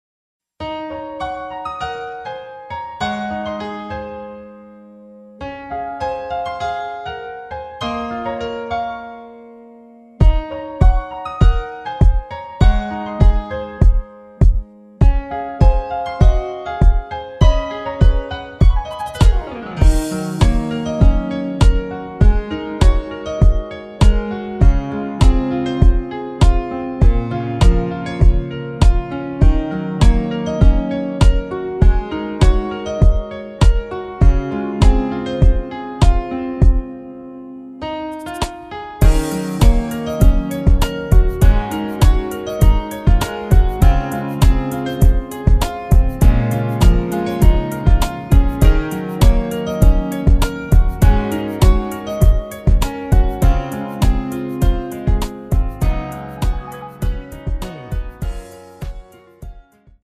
Db
◈ 곡명 옆 (-1)은 반음 내림, (+1)은 반음 올림 입니다.
앞부분30초, 뒷부분30초씩 편집해서 올려 드리고 있습니다.
중간에 음이 끈어지고 다시 나오는 이유는